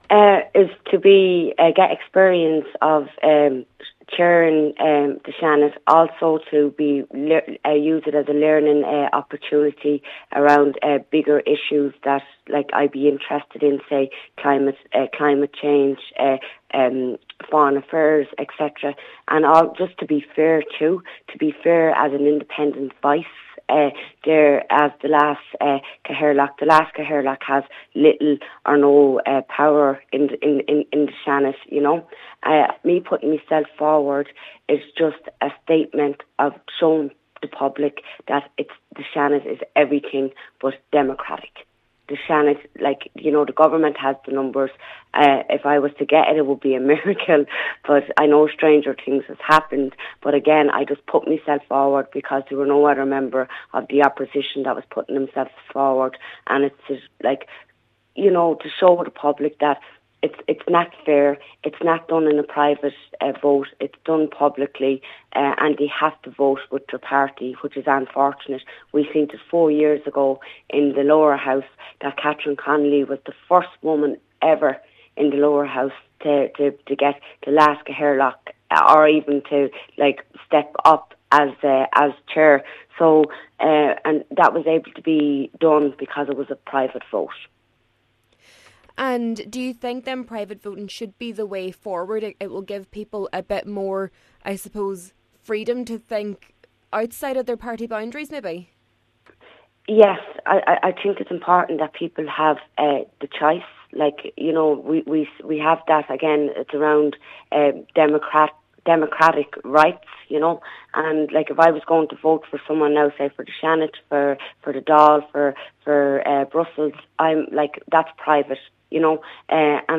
She says the ballot should be done in secret so individuals don’t feel compelled to vote within their parties: